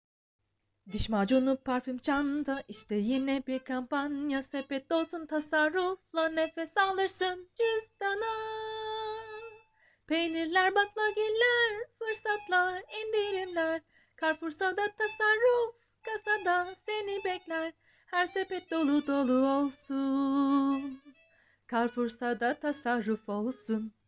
广告歌